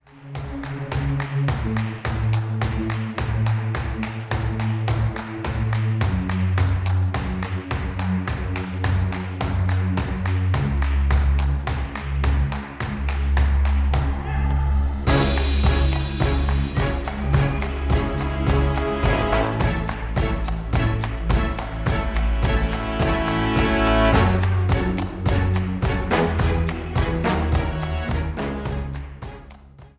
Buy With Backing Vocals.
Buy Without Backing Vocals